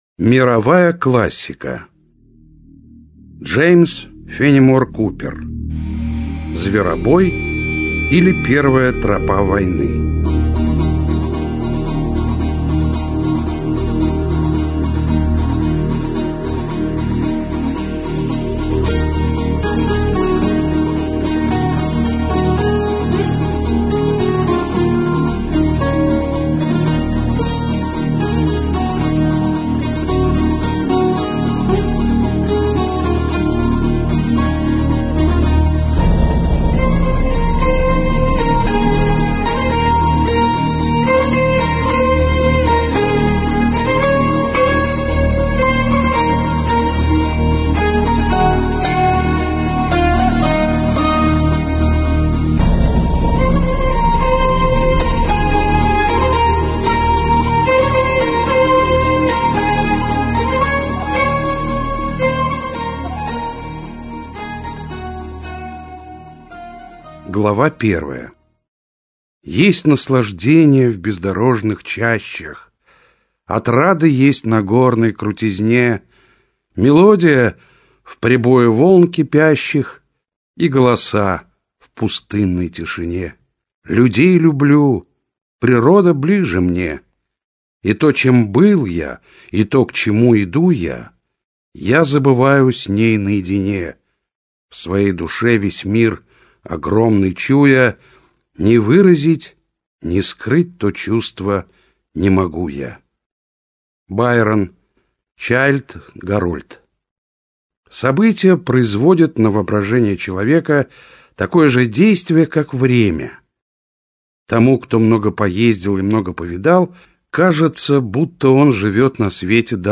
Аудиокнига Зверобой - купить, скачать и слушать онлайн | КнигоПоиск